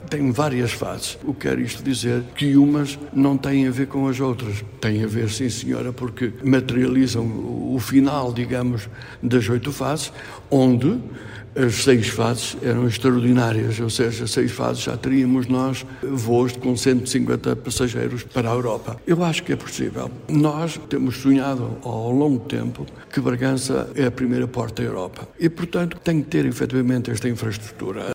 Conforme realça o presidente da câmara de Bragança, Paulo Xavier, finalmente, parece que é desta que o ambicionado aeroporto vai para a frente.
Paulo-Xavier-1.mp3